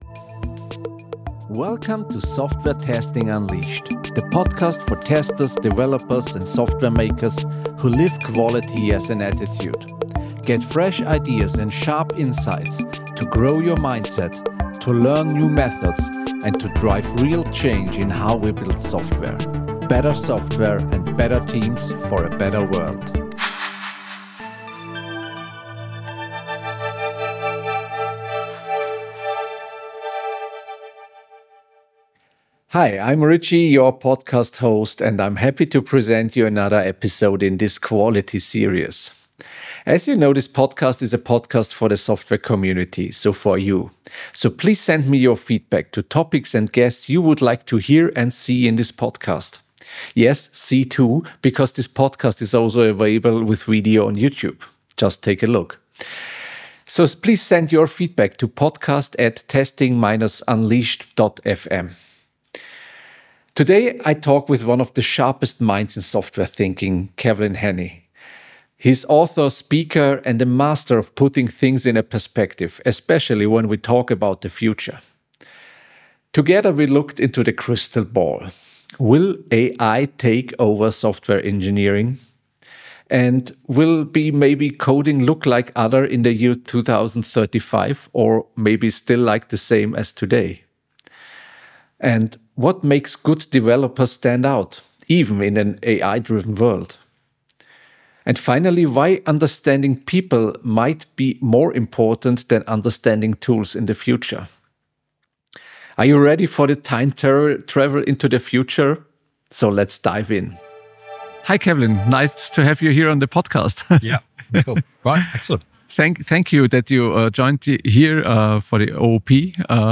Join us for a thought-provoking conversation that will make you rethink your